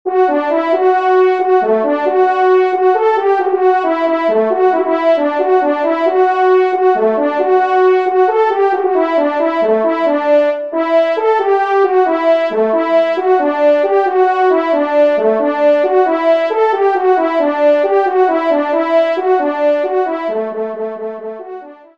Genre : Divertissement pour Trompes ou Cors
Pupitre 1° Cor